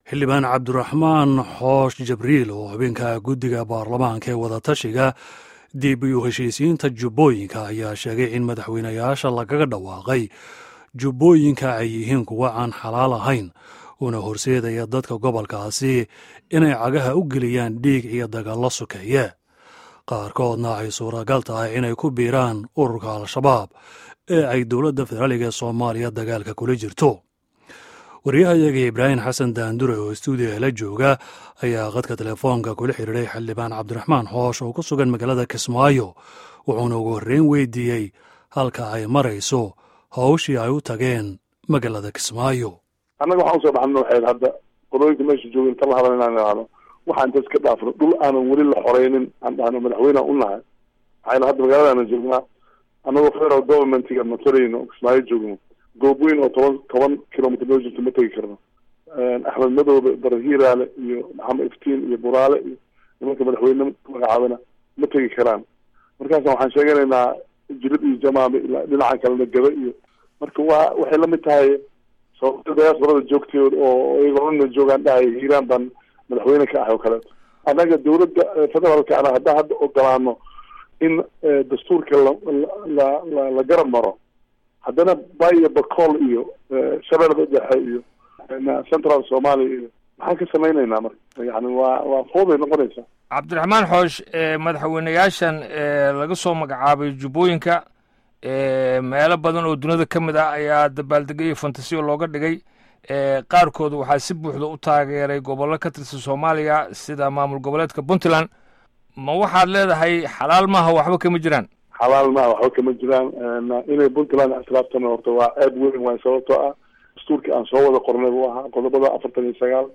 Wareysiga Xildhibaan Cabdiraxmaan Xoosh